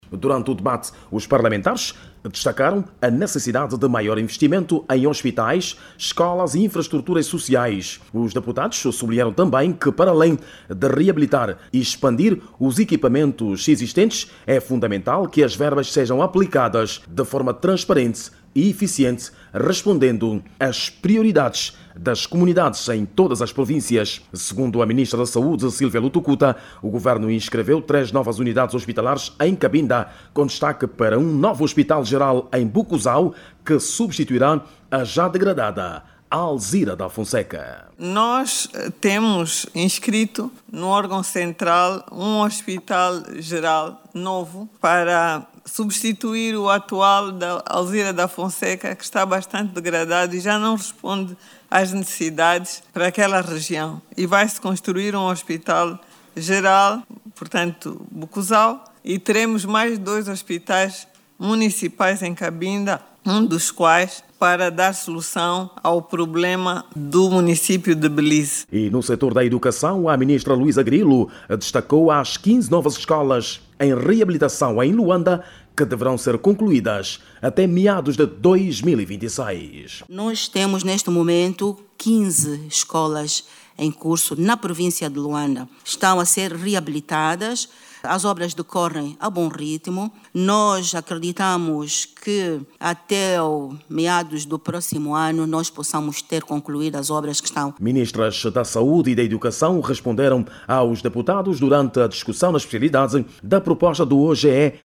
O município de Buco Zau, em Cabinda, vai ganhar um novo hospital geral que vai substituir o Hospital Alzira da Fonseca que apresenta um elevado estado de degradação. O dado foi avançado nesta quarta-feira(26), no Parlamento durante a discussão na espacialidade da proposta do OGE para 2026 onde o Executivo apresentou as prioridades do sector da saúde e educação para o próximo ano.